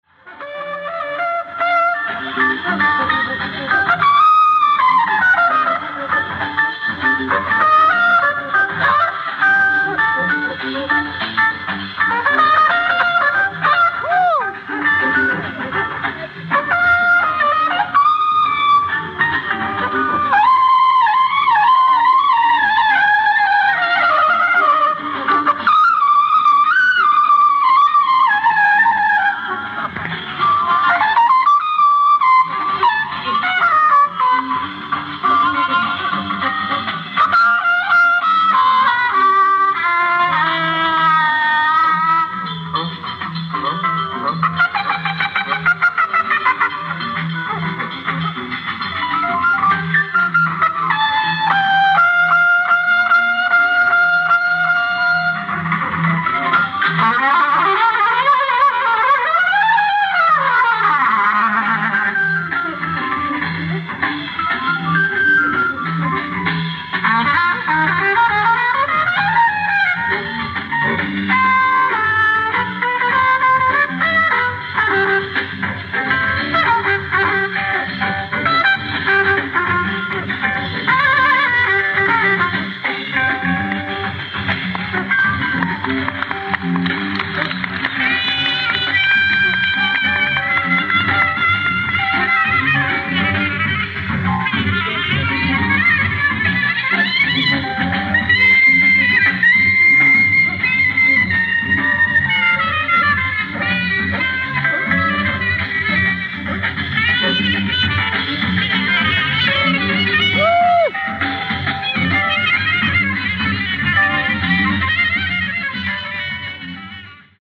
ライブ・アット・ノースウエスト・ジャズスペクタキュラー、シアトル・センターアリーナ
海外マニアのリマスター音源！！
※試聴用に実際より音質を落としています。